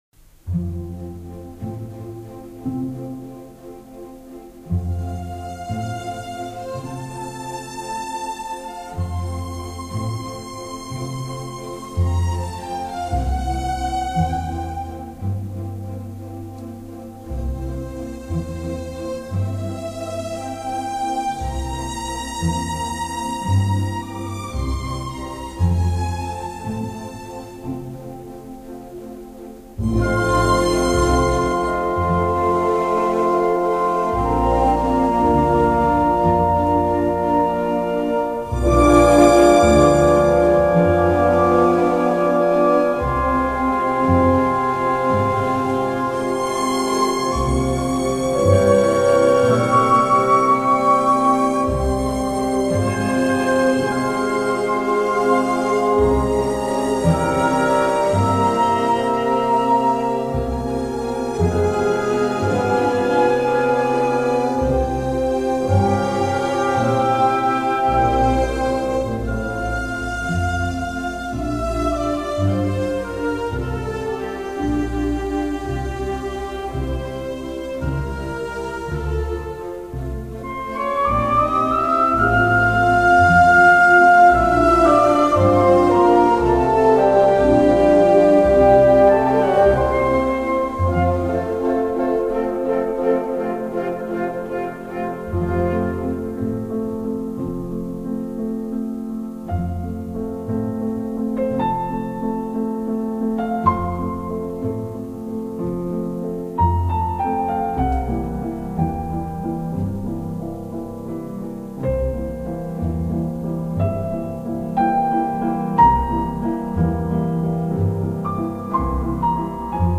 Wolfgang-Amadeus-Mozart-Piano-Concerto-No-21-Andante.mp3